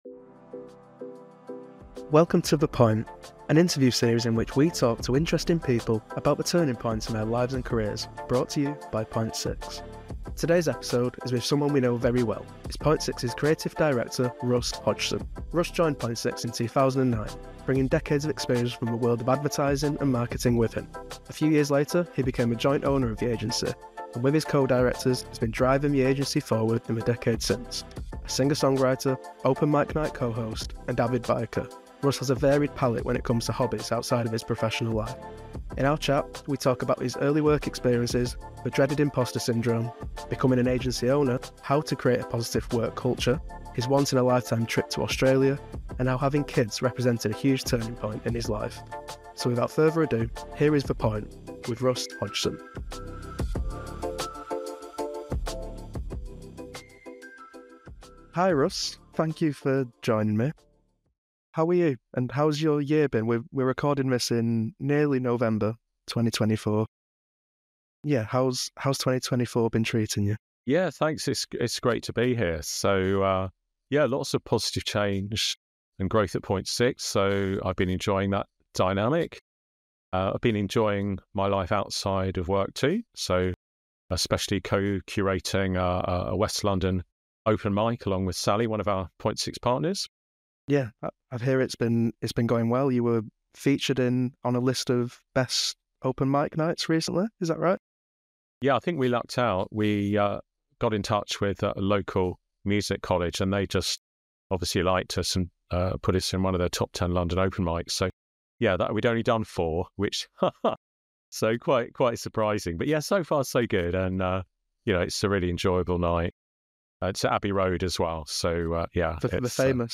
Welcome to The Point — an interview series in which we speak to interesting people about the turning points in their lives and careers.